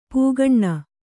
♪ pūgaṇṇa